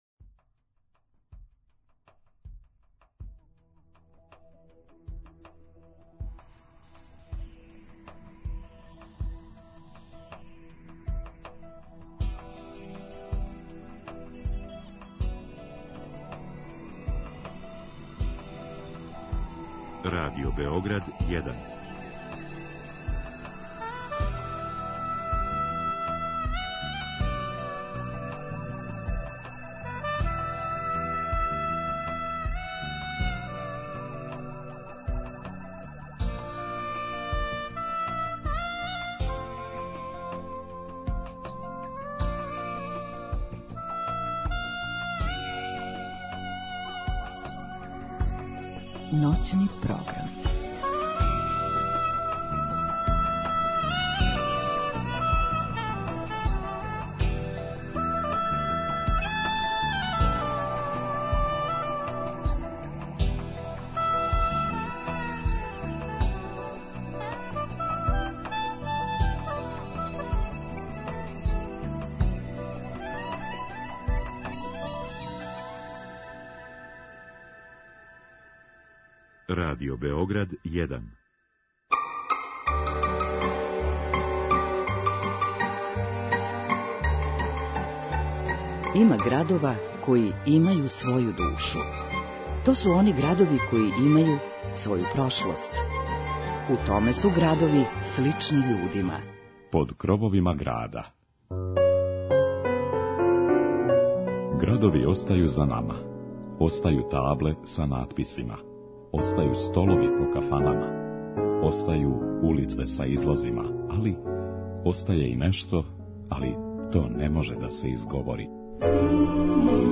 У вечерашњим 'Крововима' , музиком и причом прoвешћемо вас кроз Овчарско-кабларску клусуру и задржати се у Чачку.